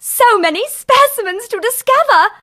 bea_start_vo_05.ogg